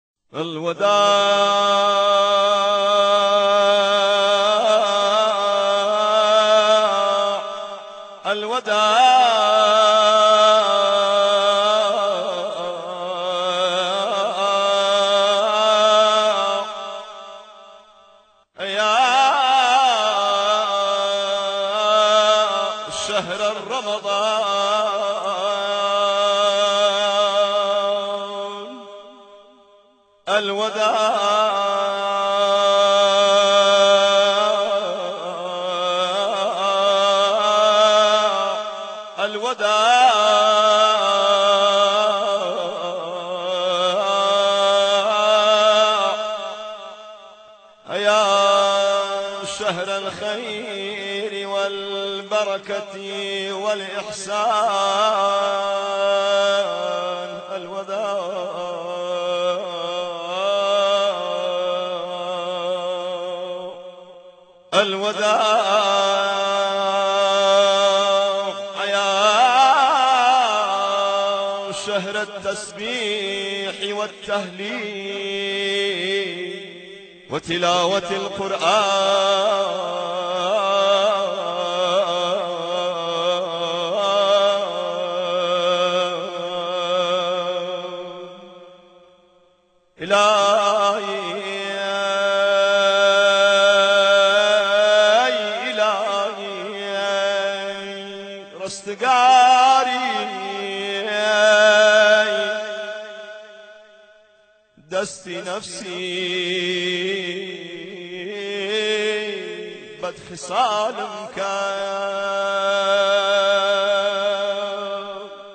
هر ساله با فرارسیدن ۱۵ ماه مبارک رمضان نوای «مرحبا مرحبا» جای خود را به نوای حزن‌انگیز «الوداع یا شهر رمضان» می‌دهد و مردم کم کم خود را برای خداحافظی با ماه رحمت و برکت الهی آماده می‌کنند.
برچسب ها: نوای الوداع ، ماه رمضان ، گلدسته‌های مساجد کردستان